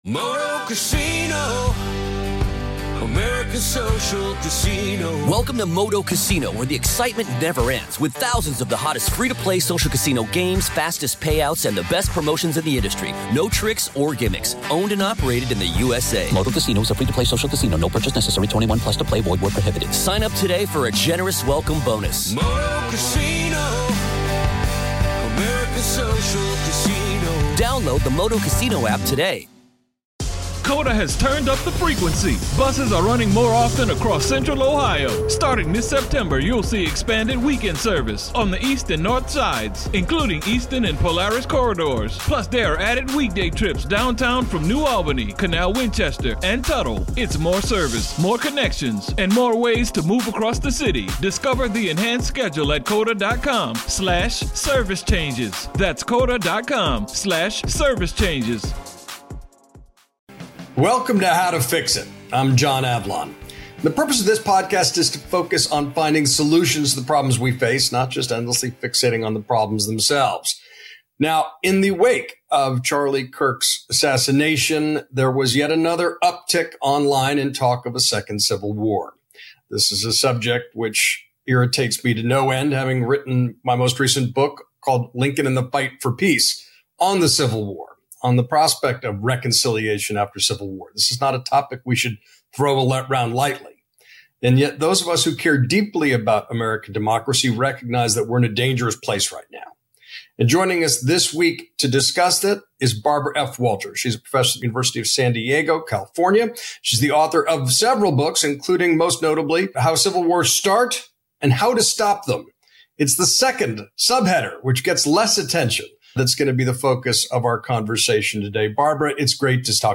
John Avlon talks with Barbara F. Walter, author of How Civil Wars Start and How to Stop Them, about the dangerous warning signs in America today. From democratic decline to political violence and Trump’s authoritarian playbook.